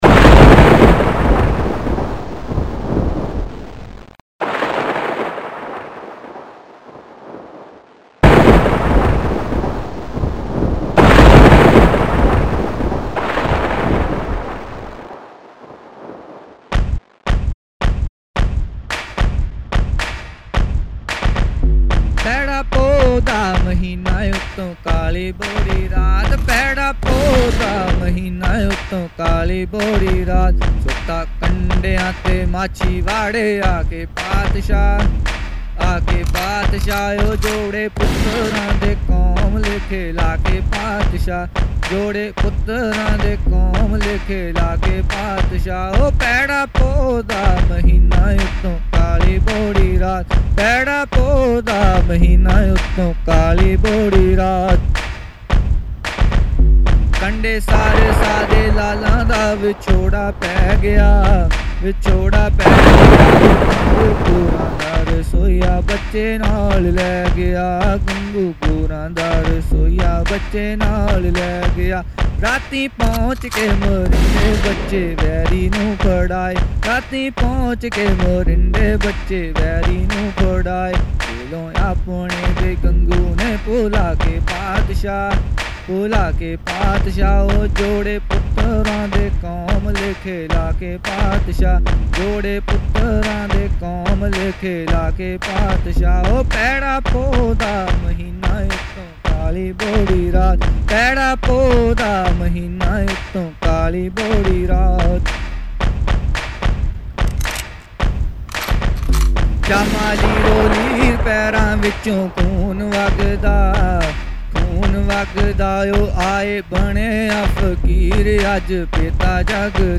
Genre: Sikh Song